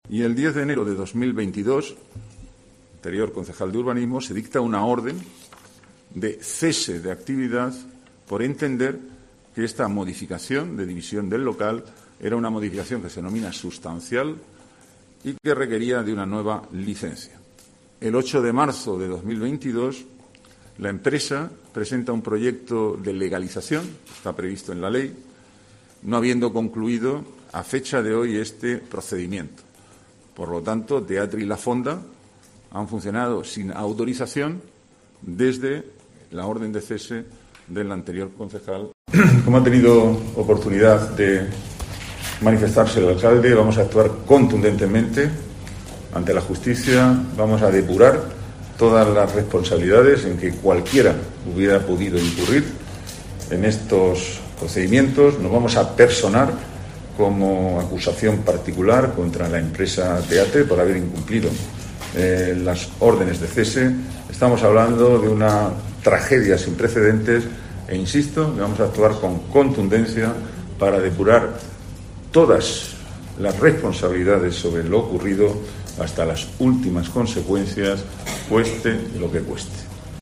Antonio Navarro, teniente de alcalde Planificación Urbanística, Huerta y Medio Ambiente